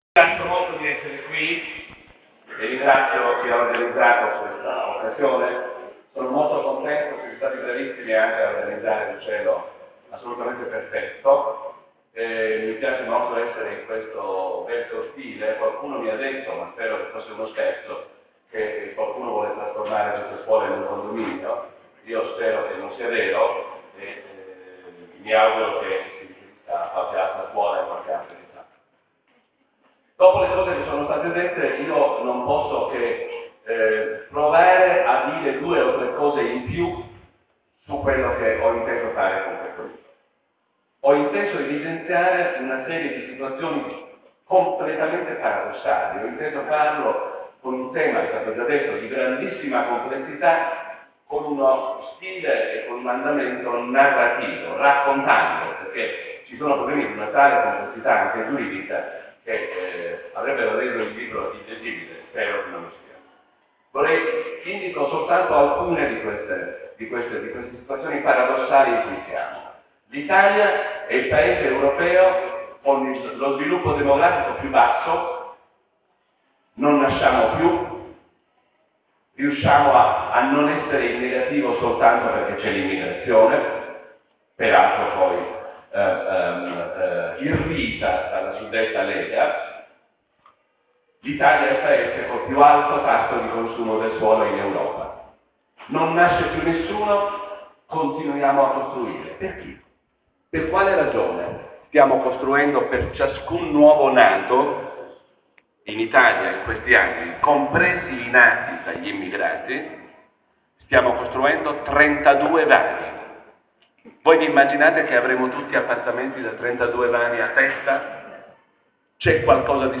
Conferenza Salvatore Settis 06 ottobre 2011 (audio)